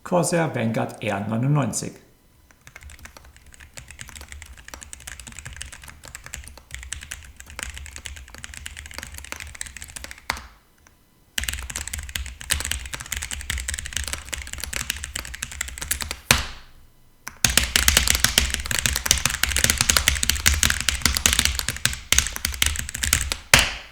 So tippen OPX Low Profile
Akustik: „Dunk“ statt „Klack“
Das Zusammenspiel von verringertem Körpervolumen in Tastern, Gehäuse und Kappen sowie dem eingebrachten Material produziert satte, dumpfe Anschläge mit leisem, klarem Klack auch beim Antippen einer Taste und eine insgesamt ruhige Tastatur.
Lediglich die Leertaste fällt durch leises Ticken ihres Stabilisators negativ auf.